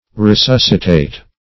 resuscitate - definition of resuscitate - synonyms, pronunciation, spelling from Free Dictionary
Resuscitate \Re*sus"ci*tate\, a. [L. resuscitatus, p. p. of